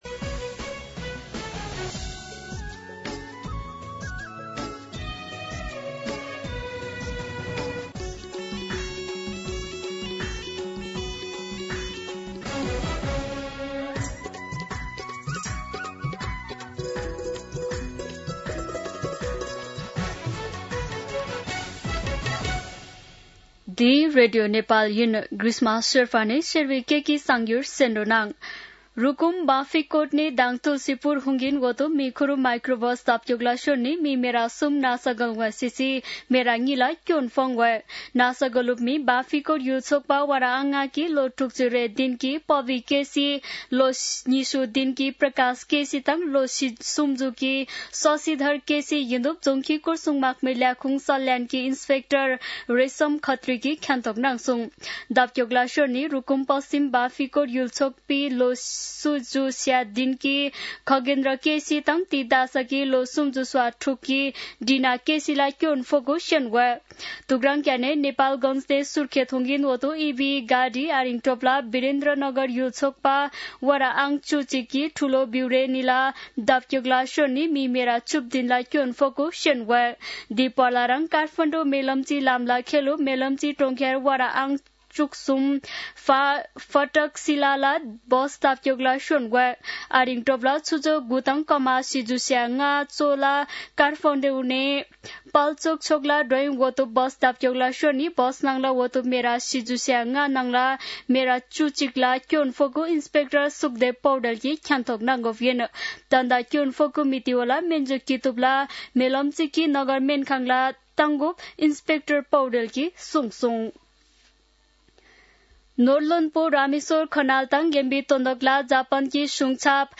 शेर्पा भाषाको समाचार : १२ असोज , २०८२
Sherpa-News-06-12-.mp3